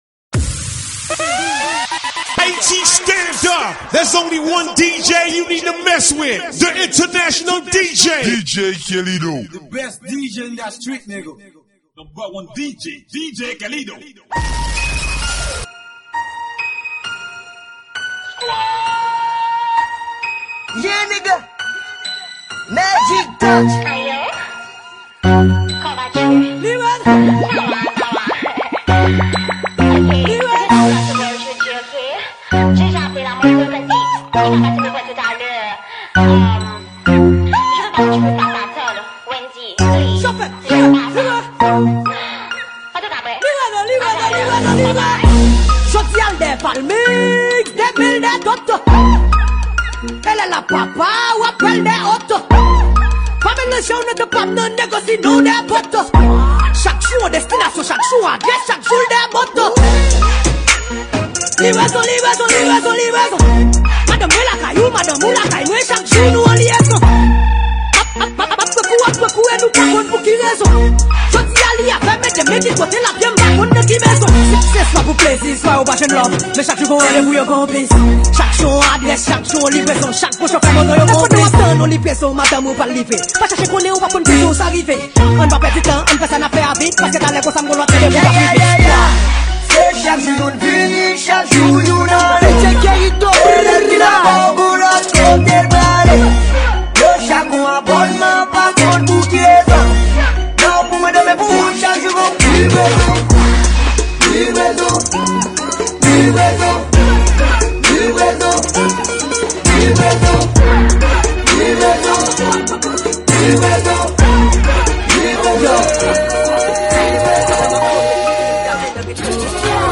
Genre: mixes.